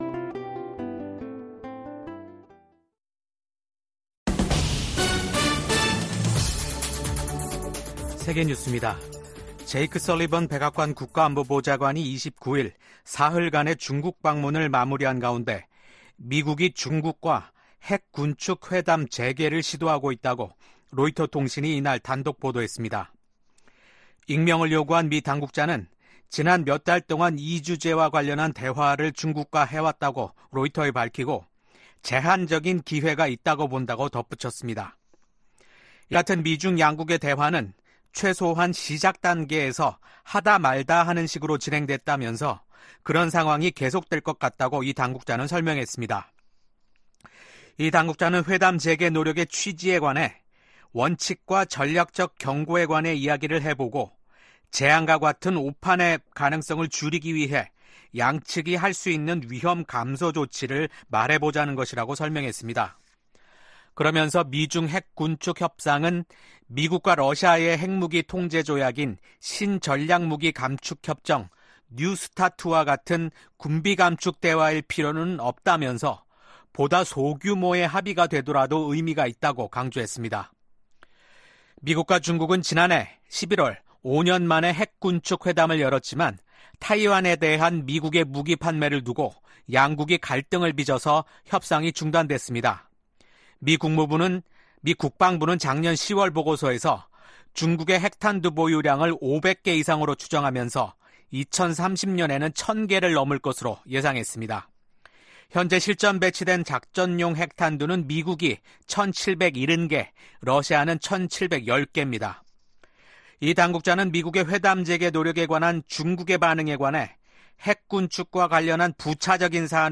VOA 한국어 아침 뉴스 프로그램 '워싱턴 뉴스 광장' 2024년 8월 30일 방송입니다. 미국 국무부는 북한의 잠수함 국제해사기구(IMO)에 등록 사실을 인지하고 있다며 북한의 불법 무기프로그램을 규탄한다고 밝혔습니다. 미국 국가안보보좌관이 중국 외교부장과 만나 다양한 현안에 대한 솔직하고 건설적인 대화를 나눴다고 백악관이 밝혔습니다. 윤석열 한국 대통령은 29일 미한일 정상의 캠프 데이비드 협력은 지도자가 바뀌더라도 변하지 않을 것이라고 밝혔습니다.